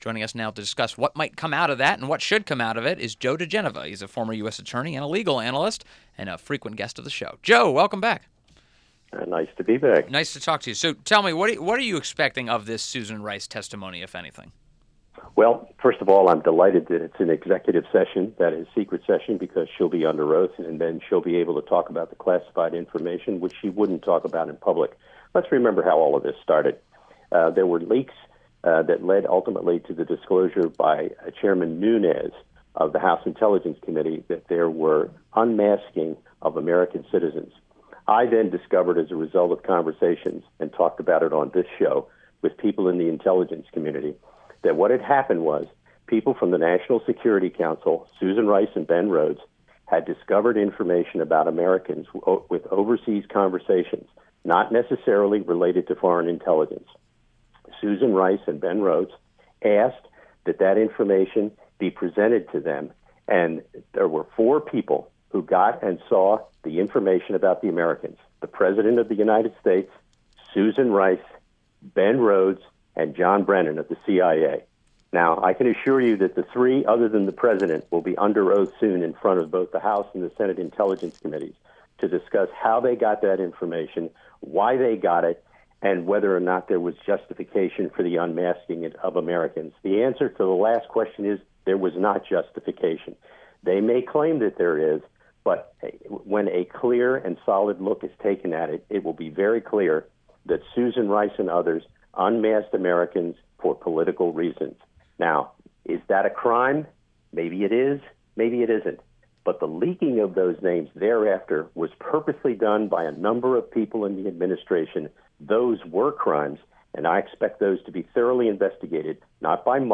WMAL Interview - JOE DIGENOVA 07.03.17